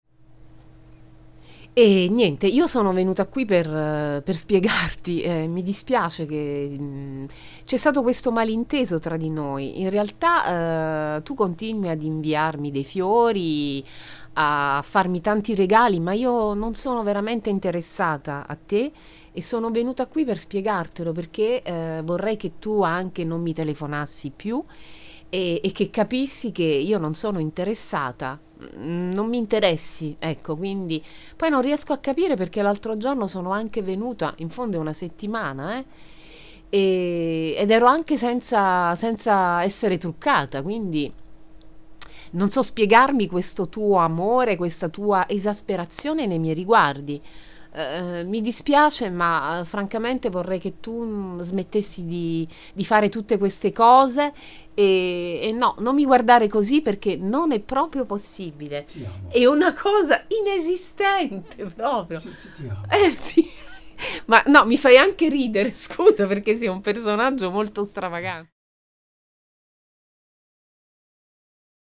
Repousser un prétendant - Voix off italienne